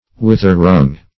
Wither-wrung \With"er-wrung`\, a. Injured or hurt in the withers, as a horse.